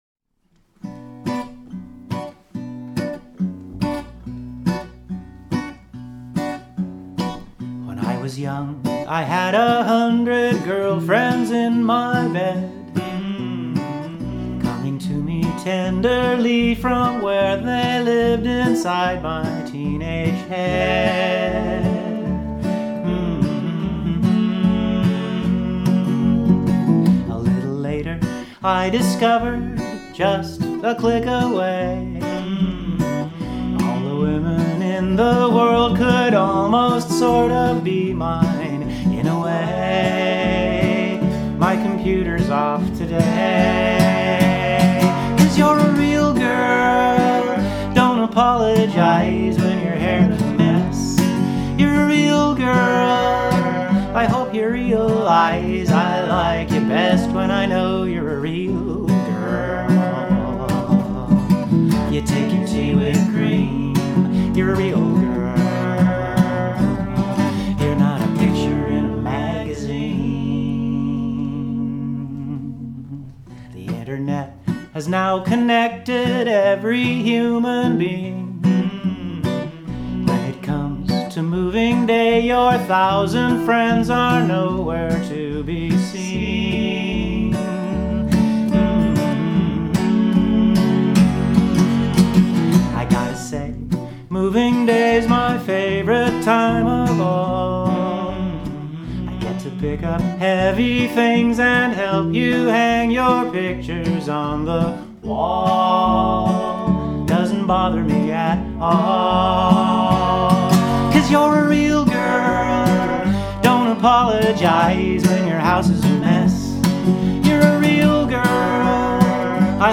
The chord progression is especially influenced by how it appears visually. I was moving colored bits of glass around throughout the process, aiming for beauty, tension and resolution. The music tells a small story, of a journey around the map.